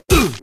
hit_cpu.ogg